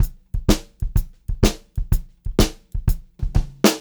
126CLBEAT1-L.wav